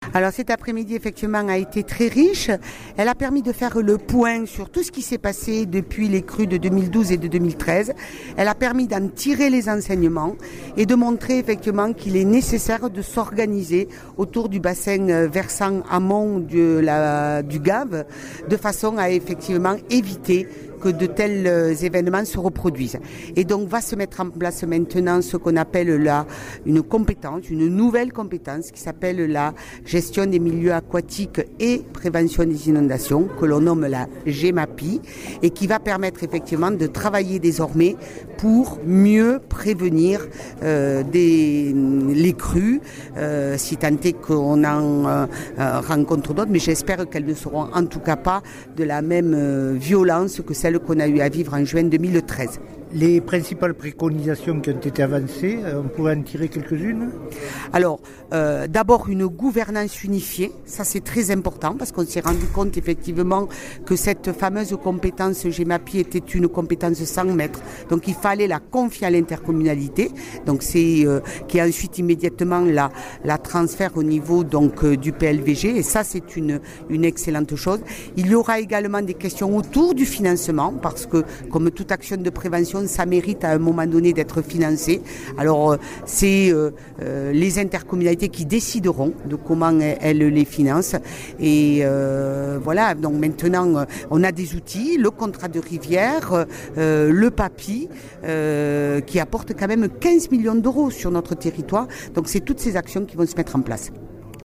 Conclusions : Interviews
Jeanine Dubié, députée (